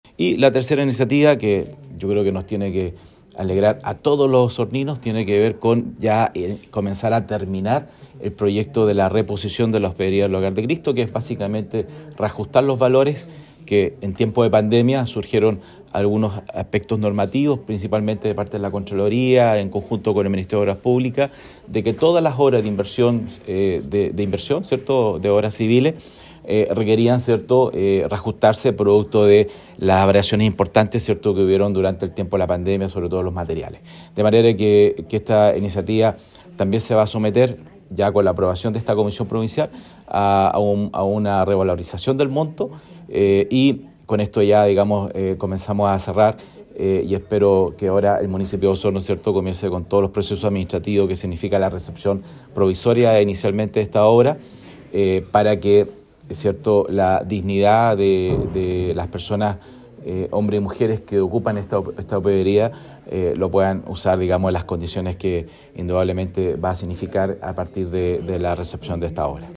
Del mismo modo el Consejero Regional explicó que se le dio relevancia al reajuste de los valores para la reposición de la Hospedería del Hogar de Cristo, que debería ser entregada en los próximos meses para su uso por parte de la fundación.